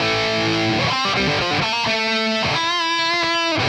Index of /musicradar/80s-heat-samples/130bpm
AM_RawkGuitar_130-A.wav